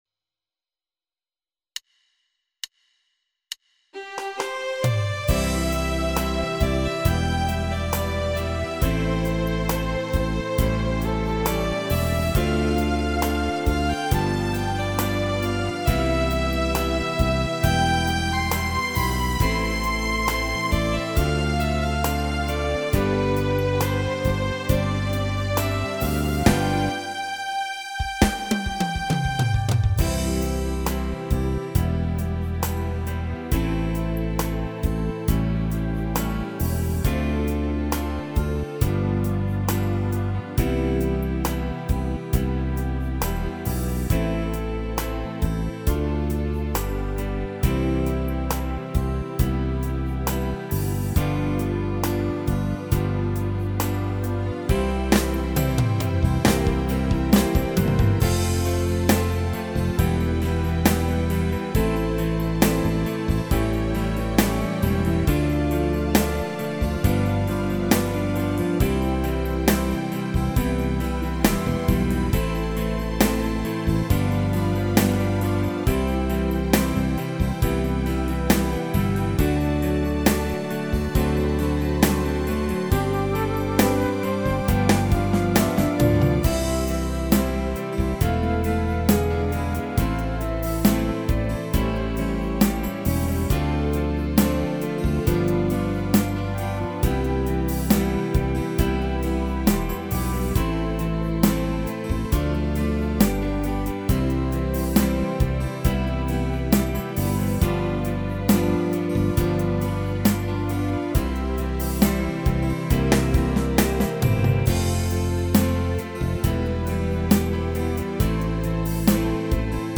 Tone Nữ (Bb) / Tốp (D)
•   Beat  02.